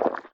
snd_slimebounce.ogg